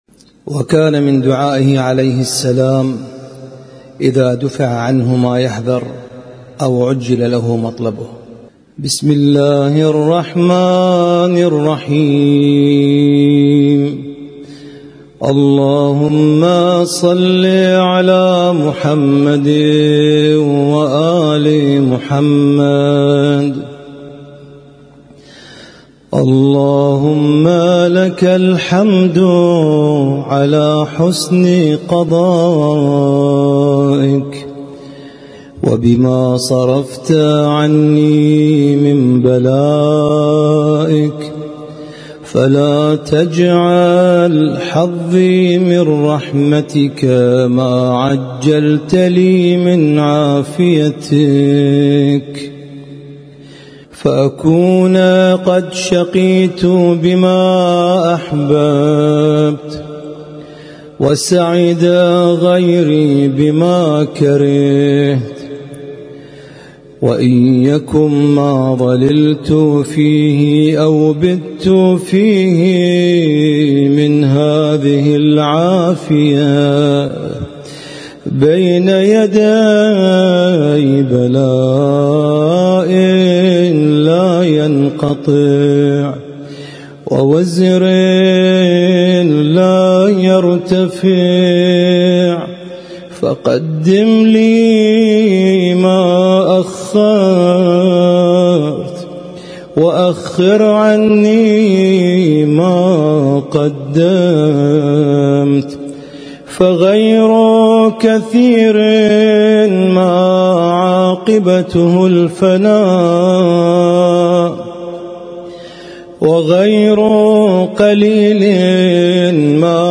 القارئ